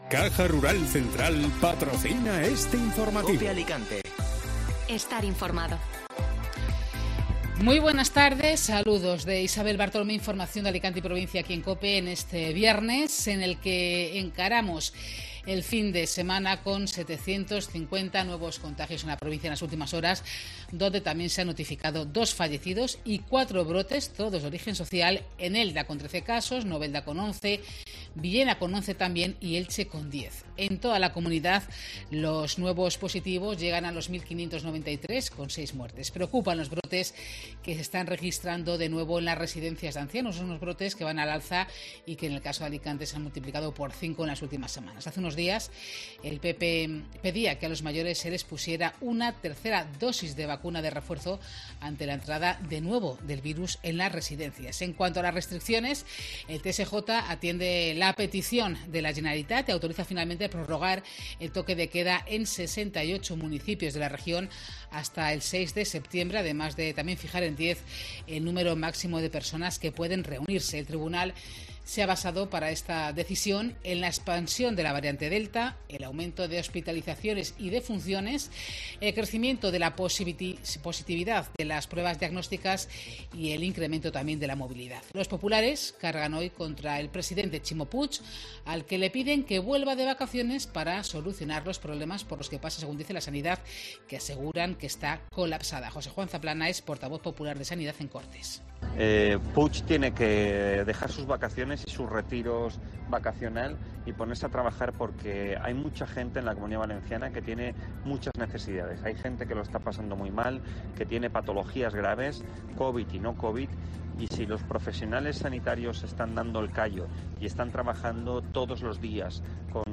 Informativo Mediodía COPE (Viernes 13 de agosto)